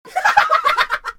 Shrieking Laughter
cackle crazy evil insane joker laugh laughing laughter sound effect free sound royalty free Funny